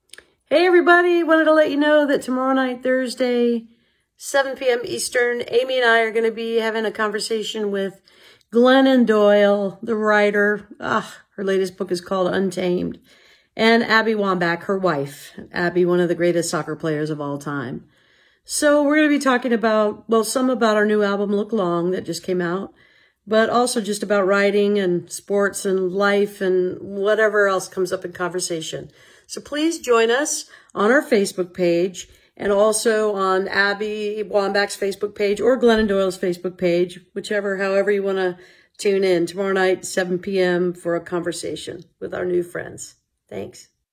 (captured from the facebook live broadcast)
01. promo (emily saliers) (0:43)